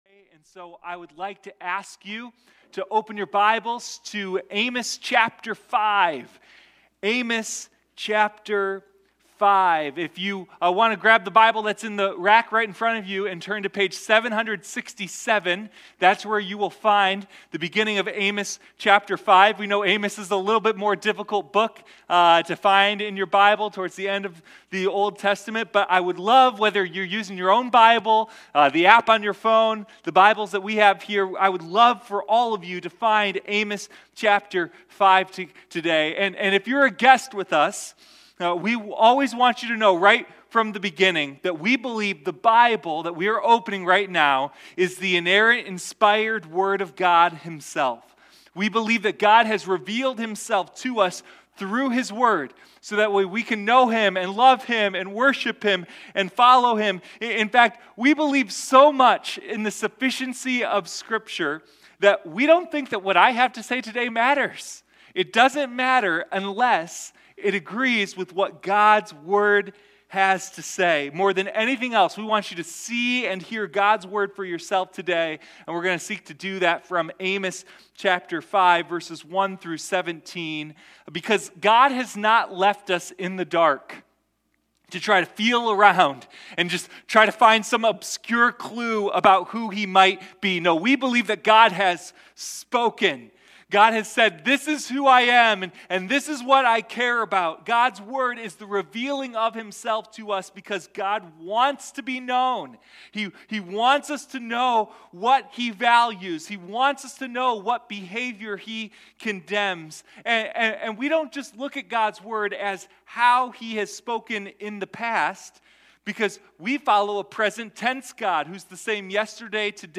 Sunday Morning Amos: The Roar of Justice